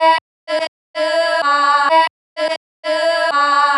• chopped vocals 109-127 female 1 (14) - Em - 111.wav